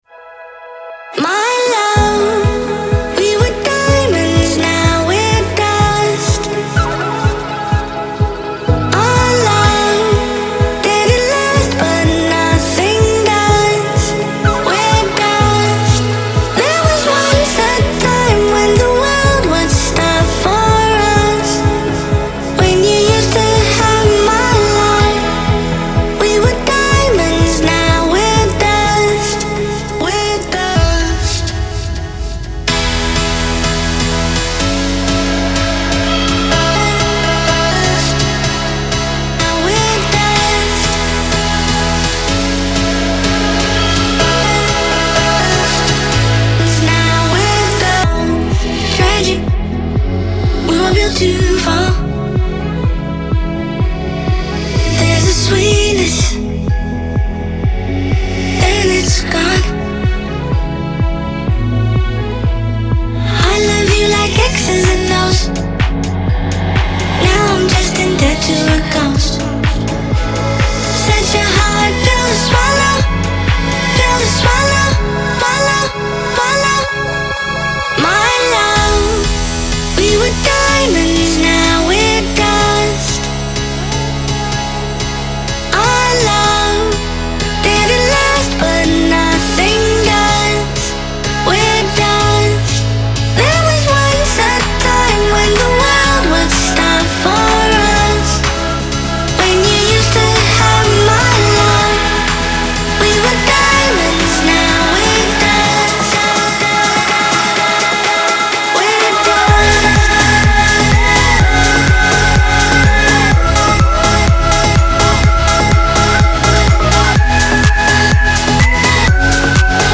• Жанр: Dance, Electronic